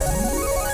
reel_spinning.wav